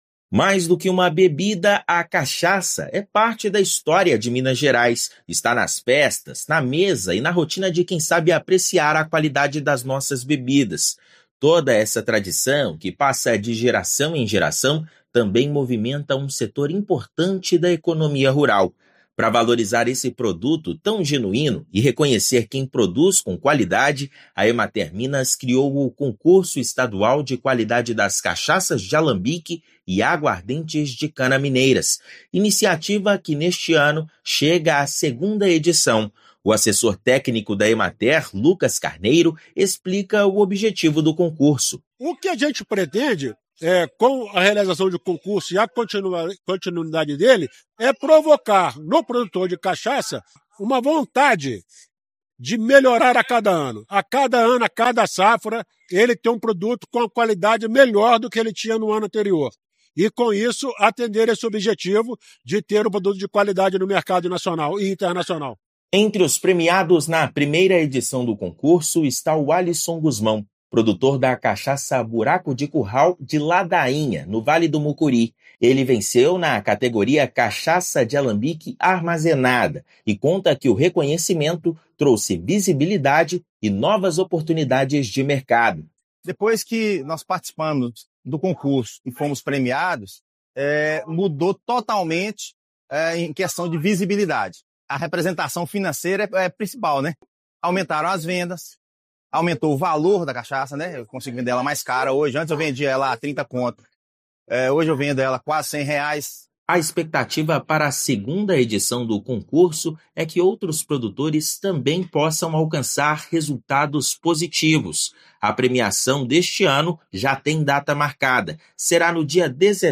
A premiação do concurso estadual do produto será realizada em 17/11. Ouça matéria de rádio.